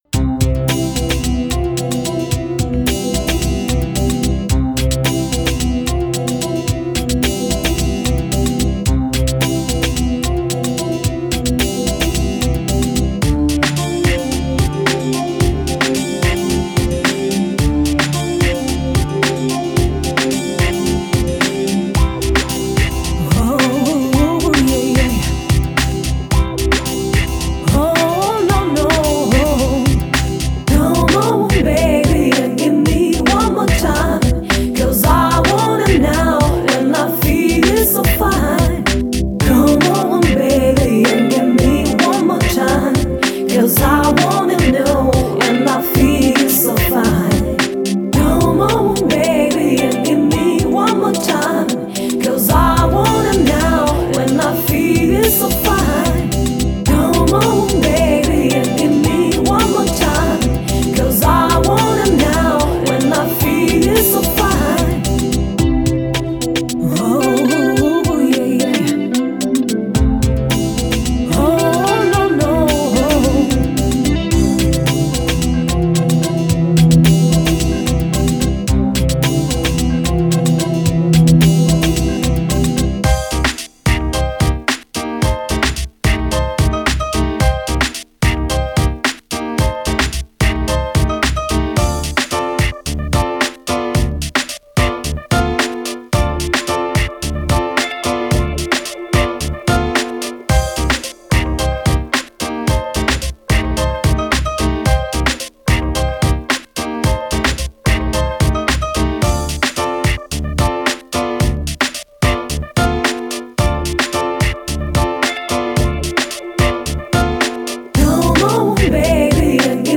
专辑类型：Lounge